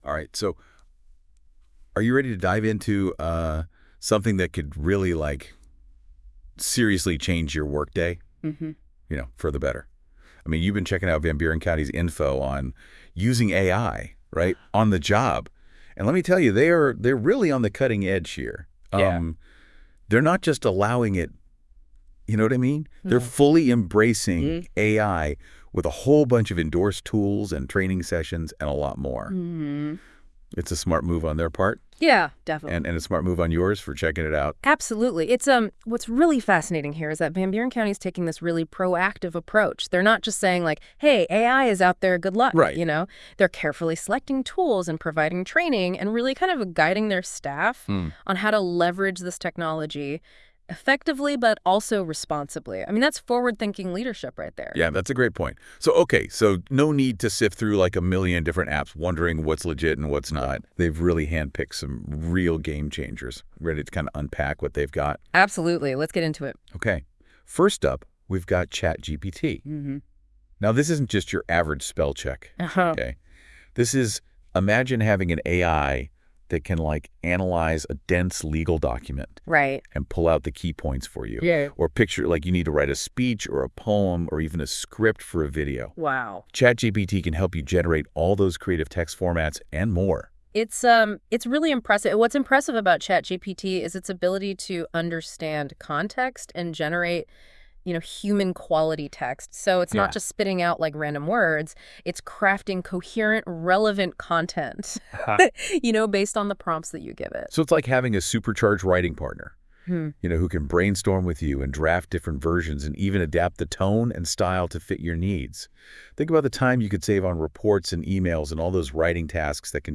Host: AI Generated by NotebookLM